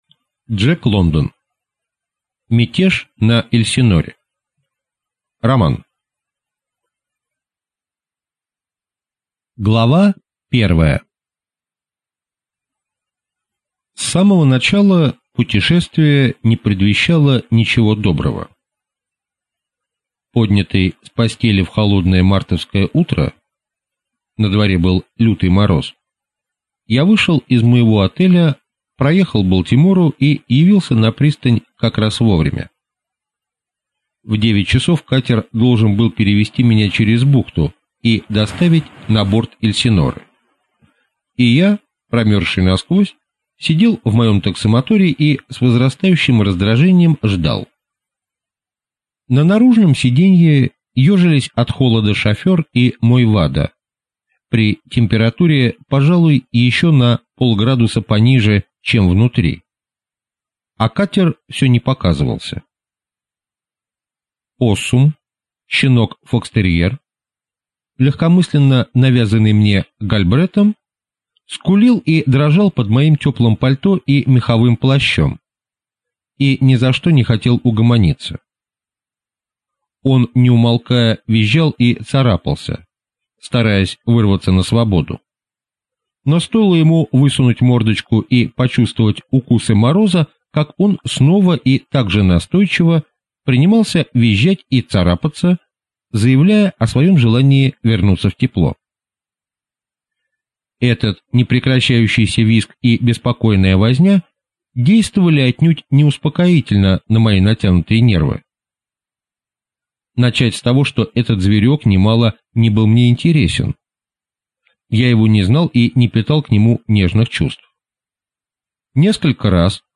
Аудиокнига Мятеж на «Эльсиноре» | Библиотека аудиокниг